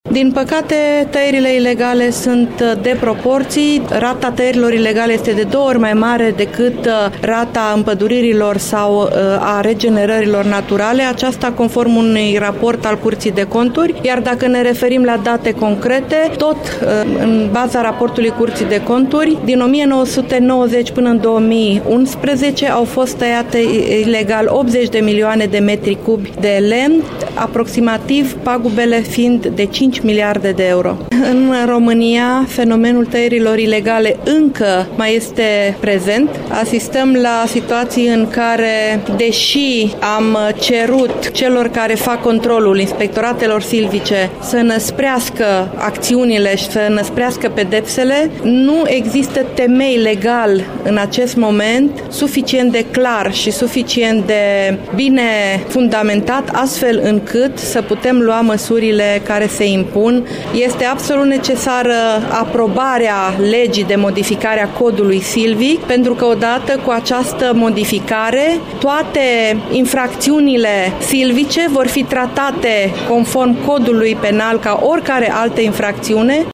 Ministrul delegat al Apelor, Pădurilor şi Pisciculturii, Lucia Varga, a fost  prezent astăzi la Arad. În timpul conferinţei de presă, ministrul a declarat că tăierile ilegale de pădure sunt de proporţii în România, practic depăşesc de două ori rata împăduririlor.